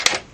lockHang.ogg